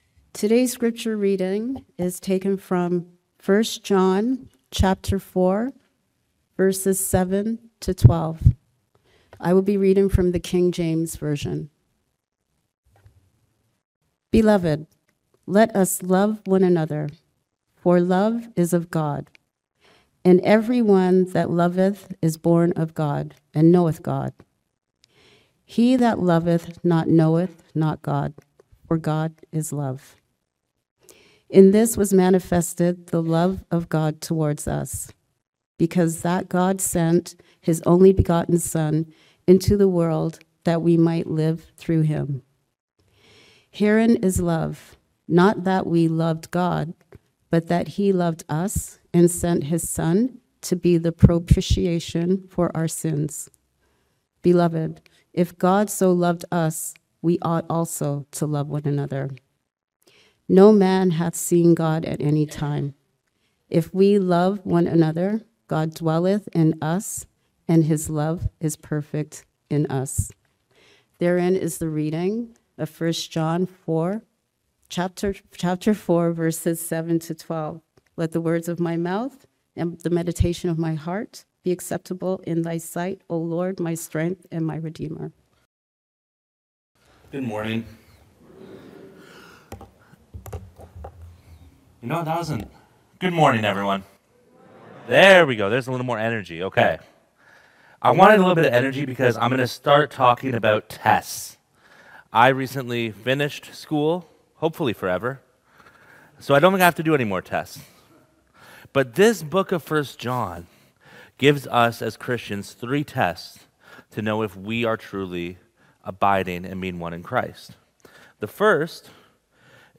Sermons | Weston Park Baptist Church